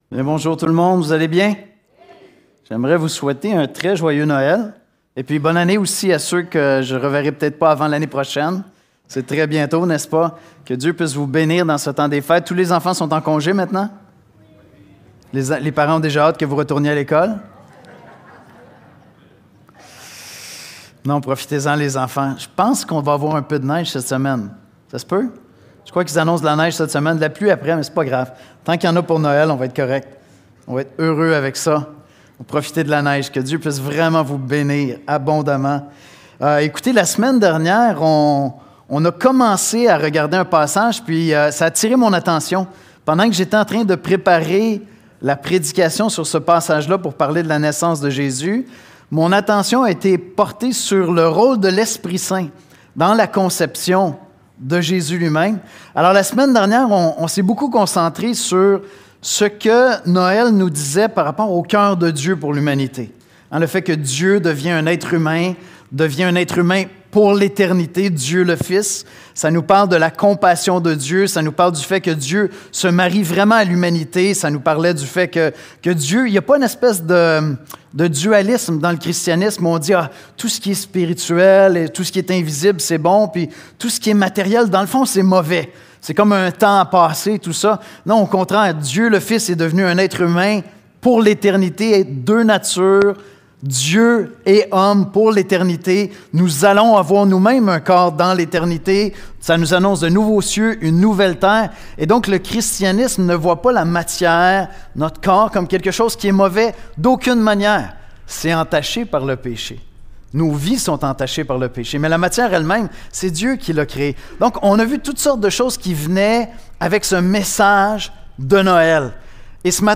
Nous vous présentons quelques unes des exposés bibliques apportés à l'Église de l'Espoir en baladodiffusion.